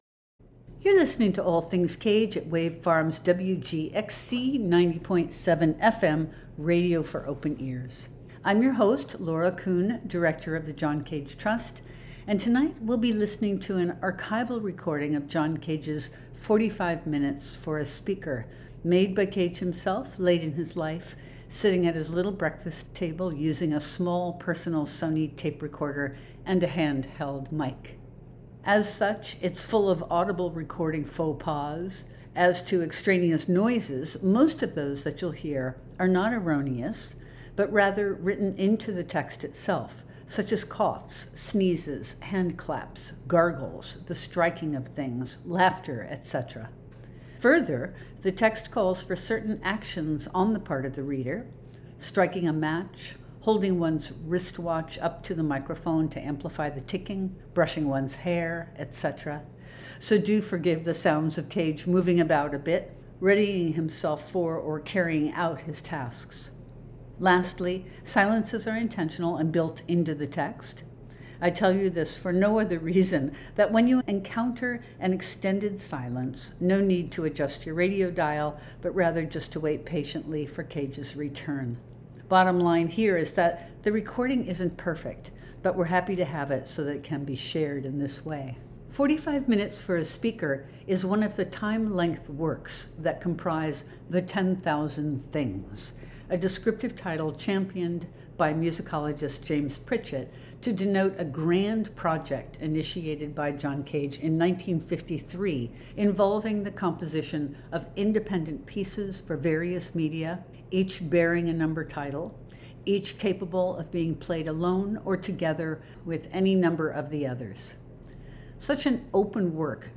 Tonight’s program will focus on 45’ for a Speaker , which we’ll listen to in its entirety from an archival recording Cage made himself.